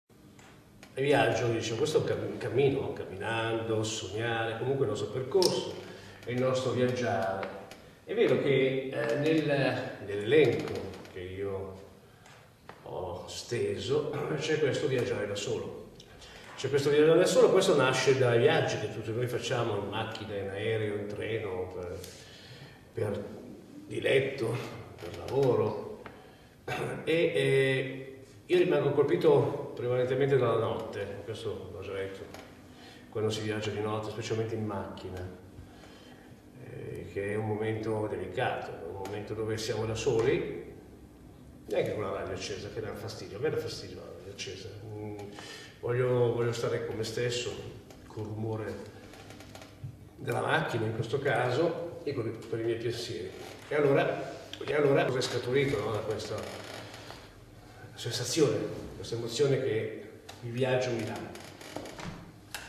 IV OTTOBRE MUSICALE A PALAZZO VALPERGA
pianista